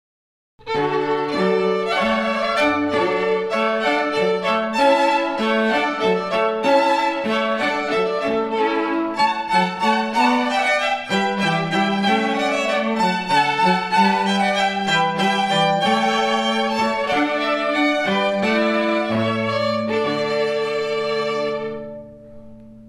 Alla Hornpipe - Water Music Handel String Quartet